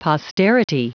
Prononciation du mot posterity en anglais (fichier audio)
Prononciation du mot : posterity